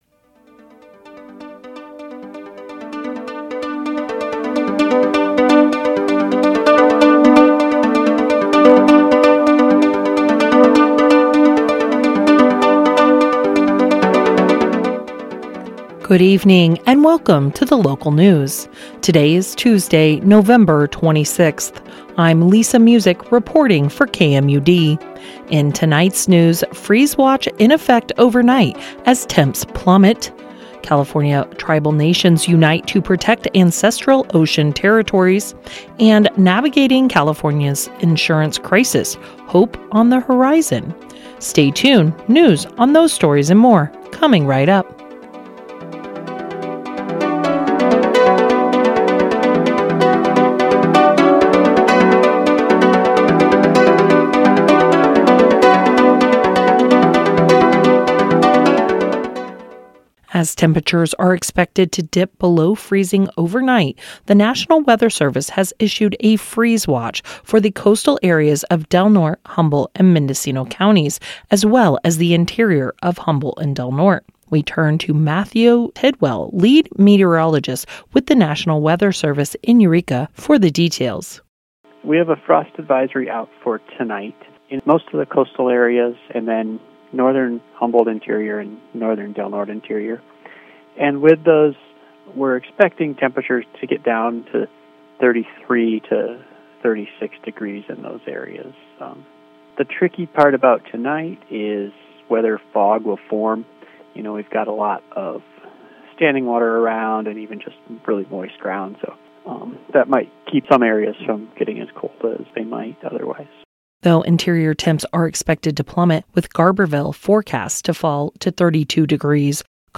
Local News Broadcast for 11/26/24